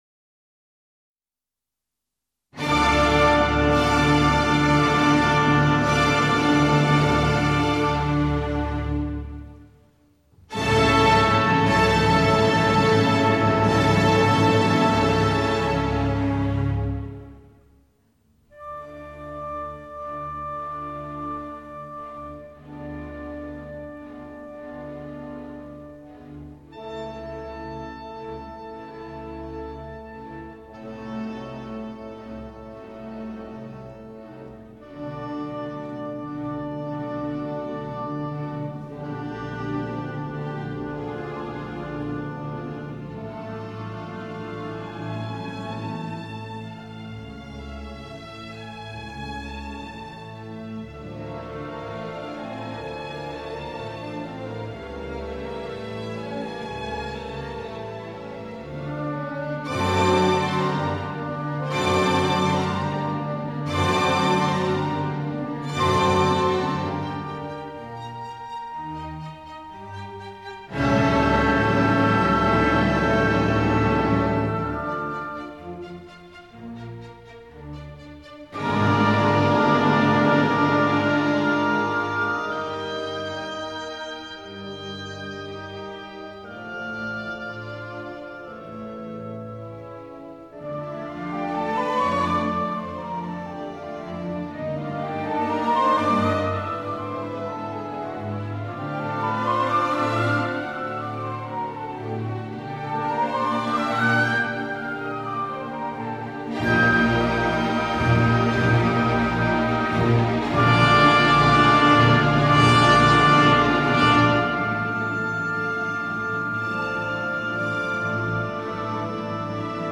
клавесин
Запись: июнь-июль 1966 года, Лондон.